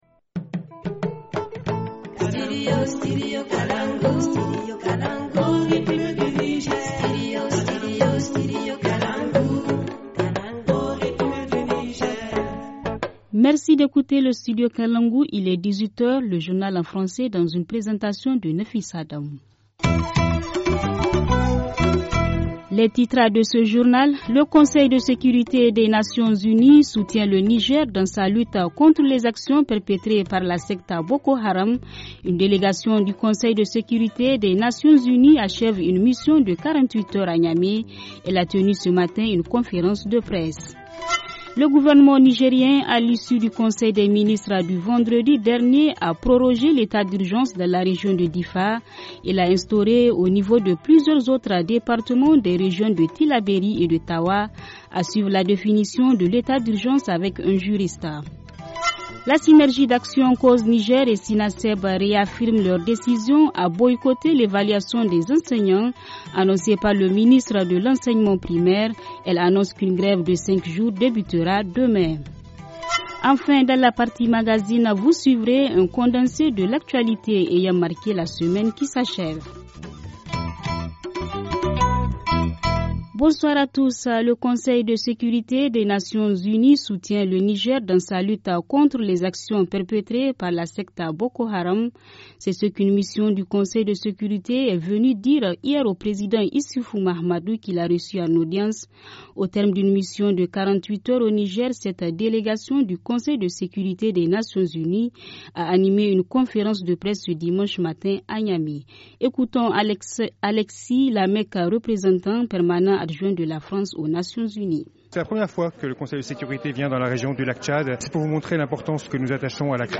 A suivre la définition de l’état d’urgence avec un juriste.